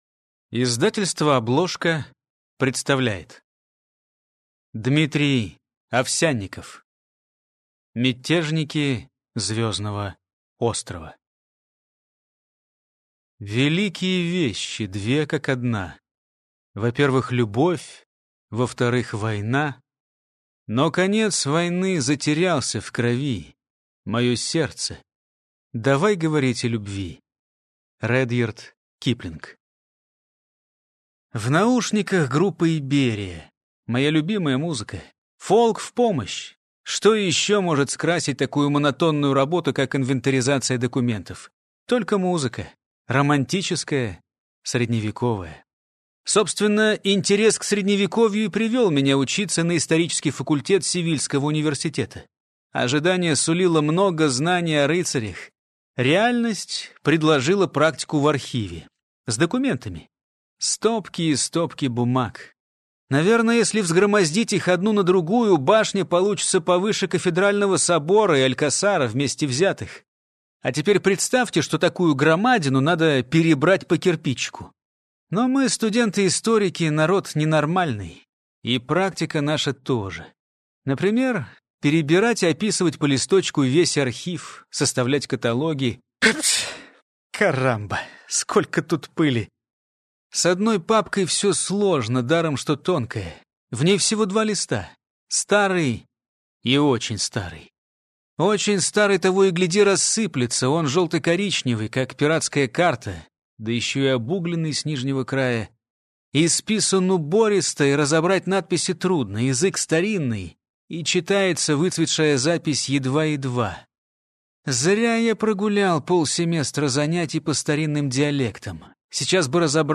Аудиокнига Мятежники Звёздного острова | Библиотека аудиокниг
Прослушать и бесплатно скачать фрагмент аудиокниги